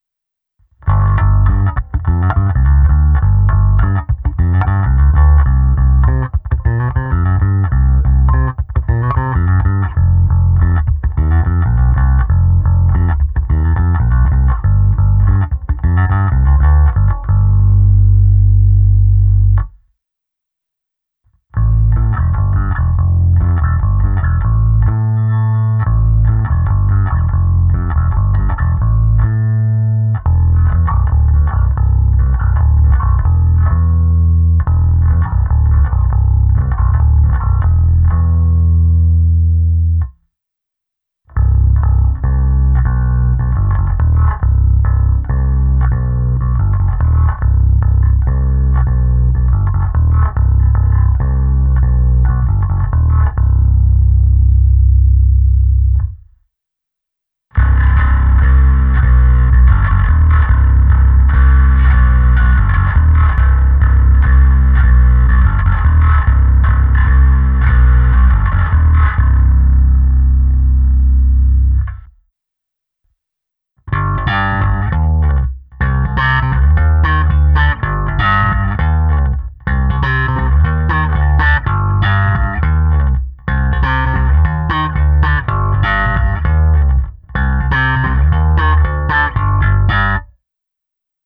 Jedná se o box Ampeg 410 HLF snímaný mikrofonem Sennheiser MD421 zapojeným do preampu Neve 1073.
EDIT: je teda hodně basový, chce to na preampu pak ty basy dost stáhnout, takže po počátečním nadšení přišlo vystřízlivění.